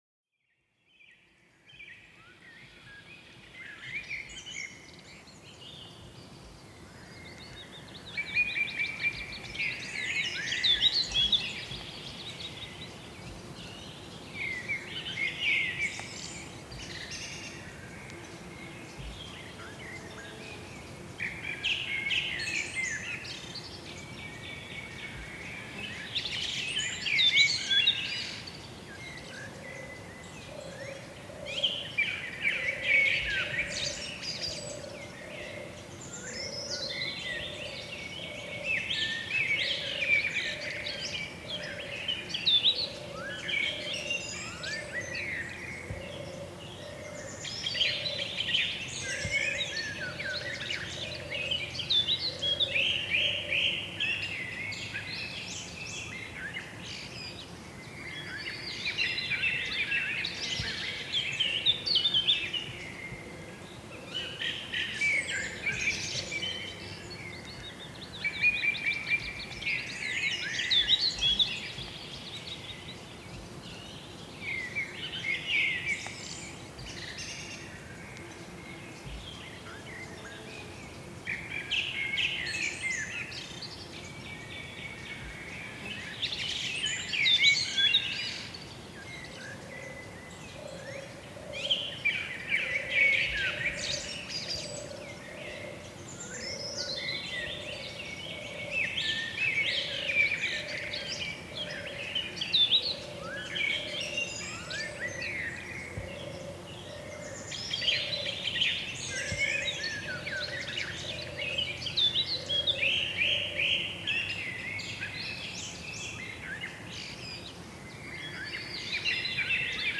Chants d'oiseaux dans une forêt calme pour apaiser l'esprit
Plongez dans une forêt tranquille où les chants d'oiseaux résonnent doucement entre les arbres. Chaque mélodie apporte une sensation de sérénité, favorisant un moment de calme intérieur.